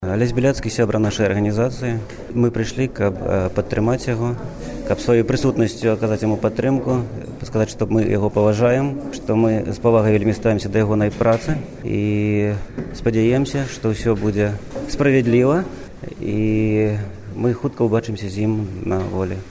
Інтэрвію